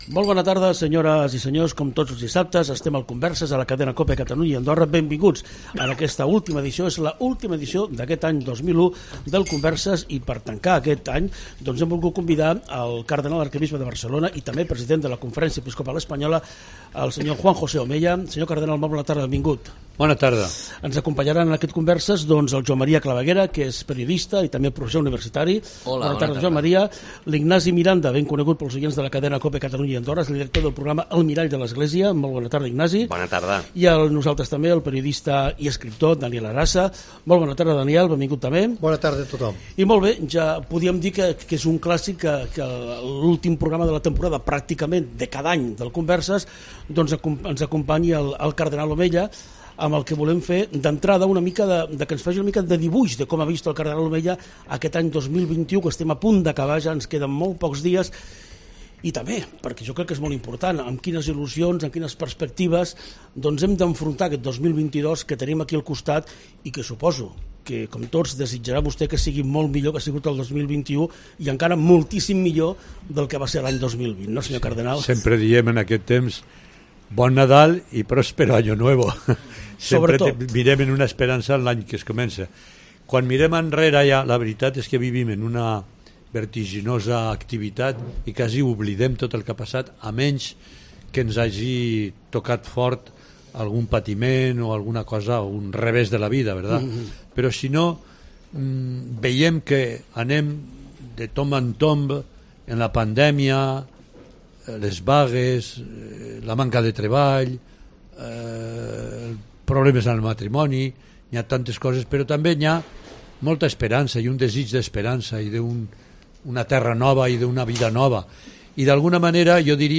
En declaraciones al programa Converses de Cope Catalunya i Andorra